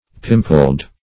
(of complexion) blemished by imperfections of the skin ; [syn: acned , pimpled , pimply , pustulate ] The Collaborative International Dictionary of English v.0.48: Pimpled \Pim"pled\, a. Having pimples.
pimpled.mp3